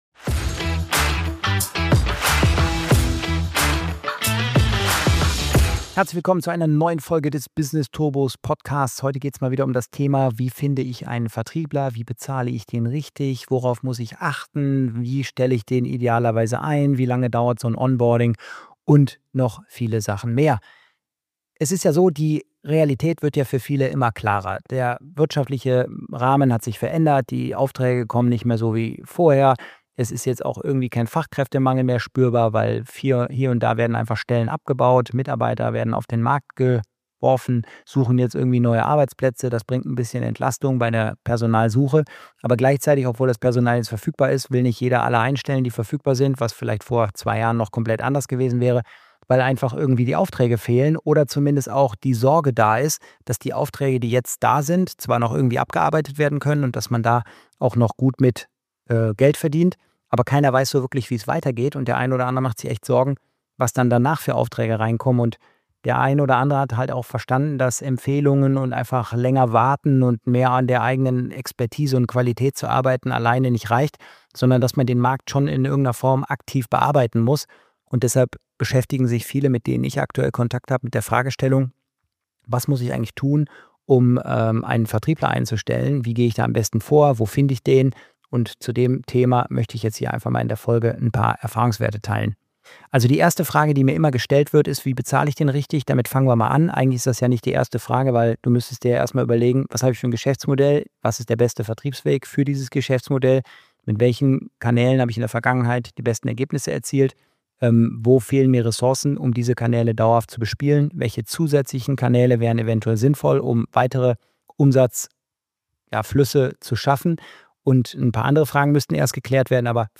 In dieser Solo-Folge spreche ich offen und ungefiltert über eine der meistgestellten Unternehmerfragen überhaupt: Wie finde ich einen guten Vertriebler – und wie bezahle ich ihn richtig?